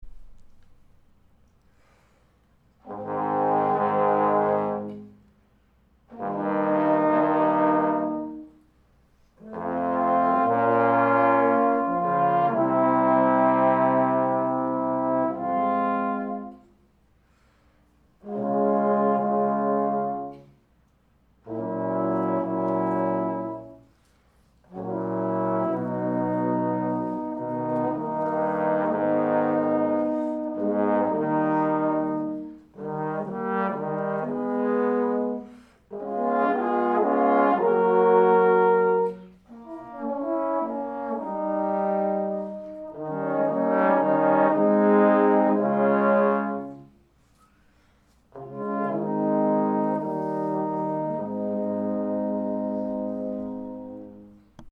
First, print out this PDF – it’s the score to the third movement of the Three Equali trombone quartet by Beethoven…
1. First part changed to the small bore student model horn